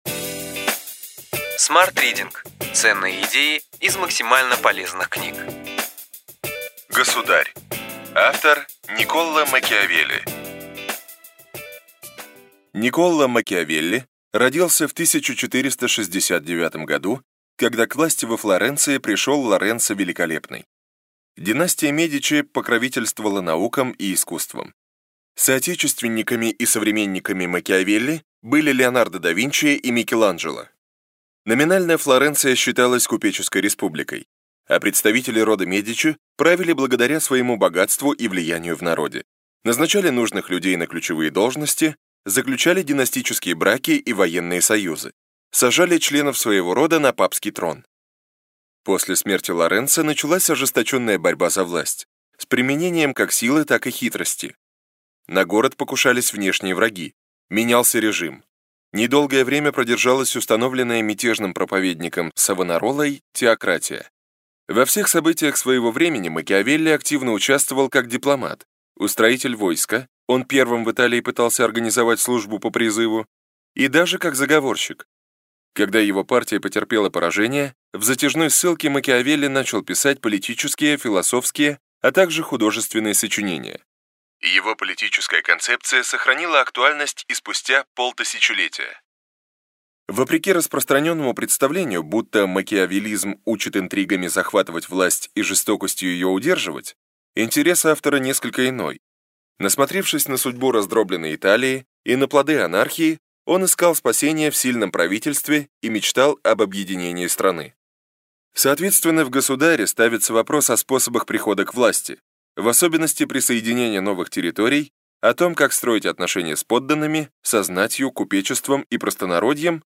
Аудиокнига Ключевые идеи книги: Государь. Никколо Макиавелли | Библиотека аудиокниг